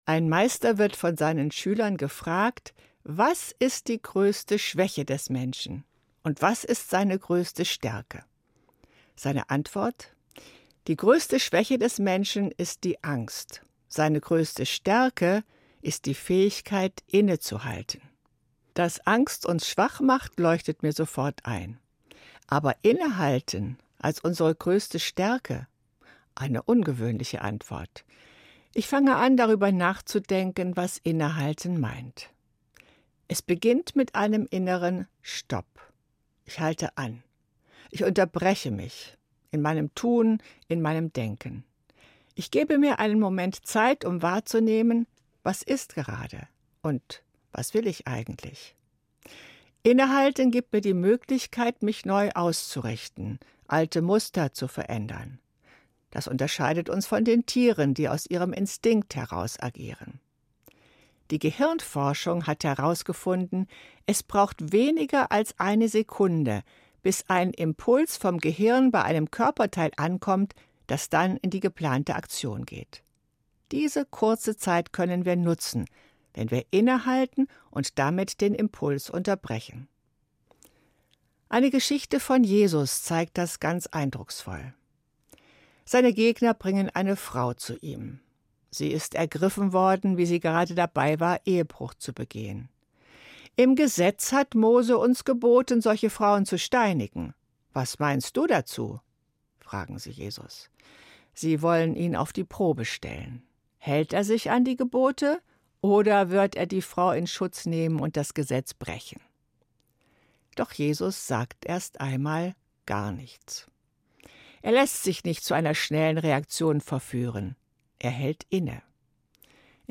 Evangelische Pfarrerin, Marburg